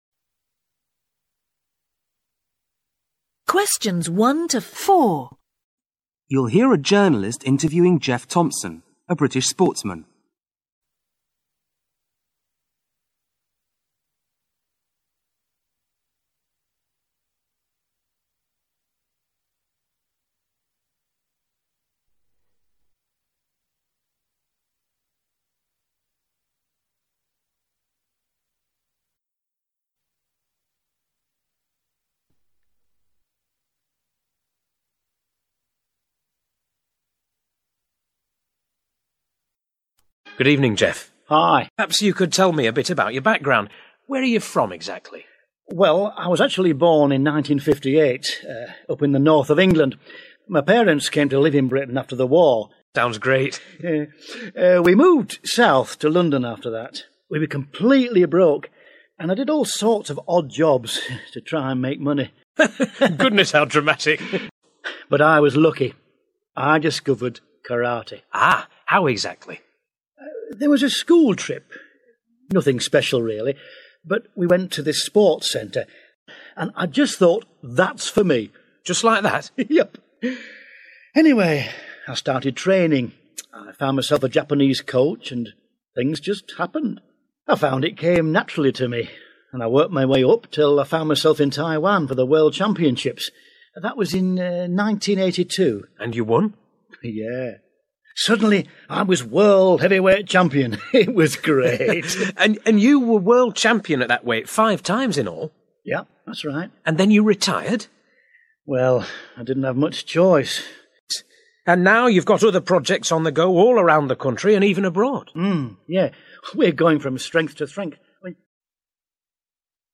Conversation 1: You will hear a journalist interviewing Geoff Thompson, a British sportsman.